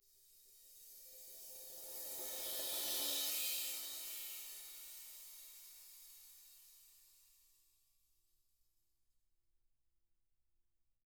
Index of /90_sSampleCDs/ILIO - Double Platinum Drums 1/CD4/Partition I/RIDE SWELLD